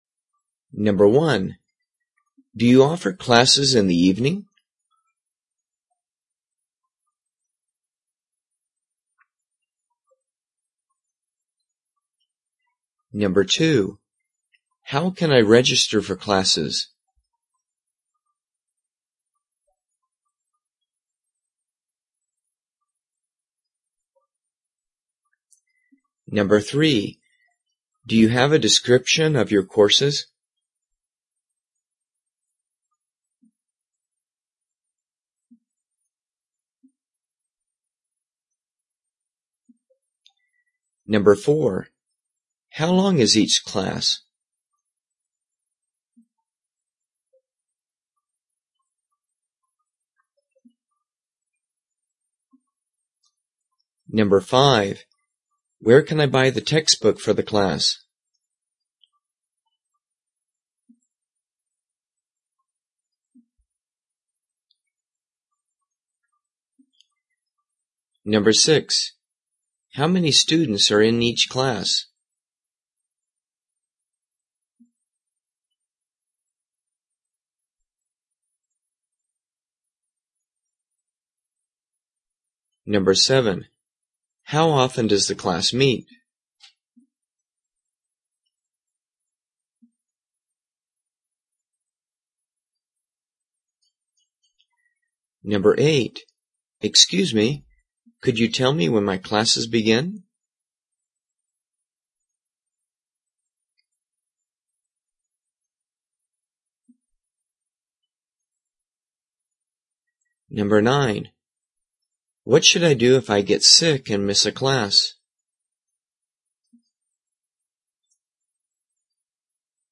英语听力练习题目：studying English Abroad